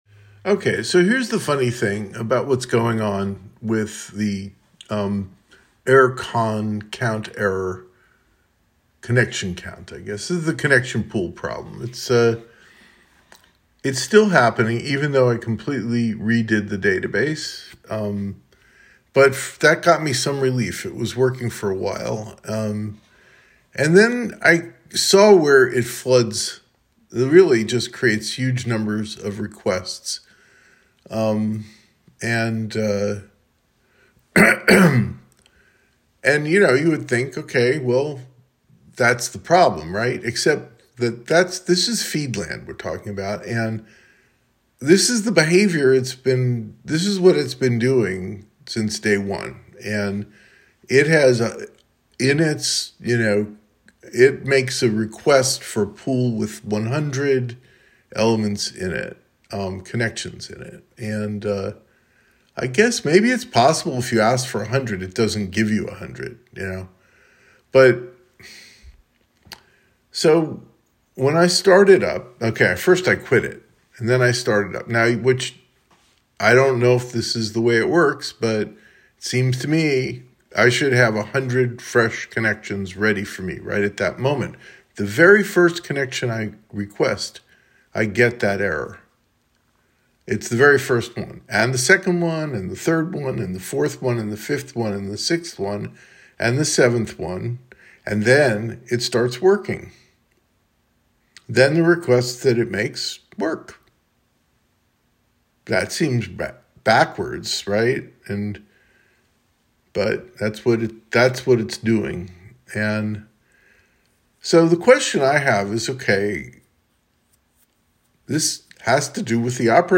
Anyway I recorded the story of what's going on here, verbally.